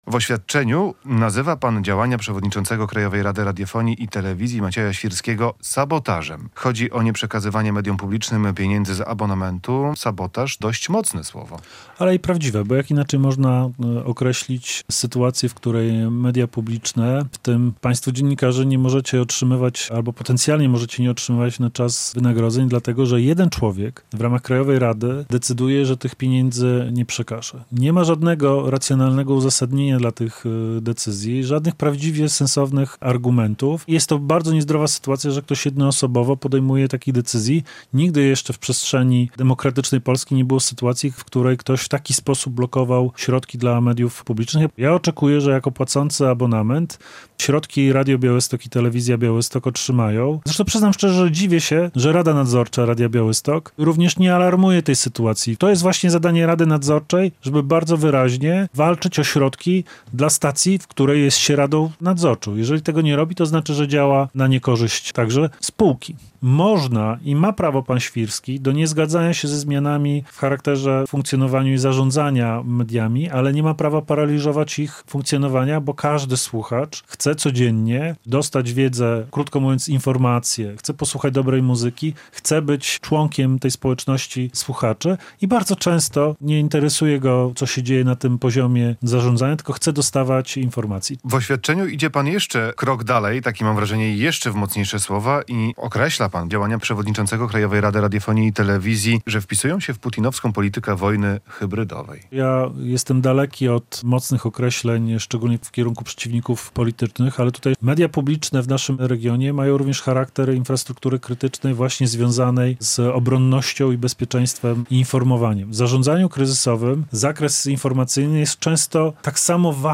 Z wicemarszałkiem Senatu Maciejem Żywno rozmawia